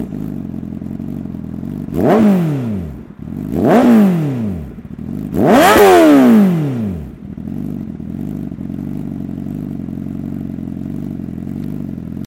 Silencieux LEOVINCE LV 10 Adapt. Honda CBR 1000 RR Fireblade 2017-2019
Niveau sonore 107.8 db à 6500 trs/mn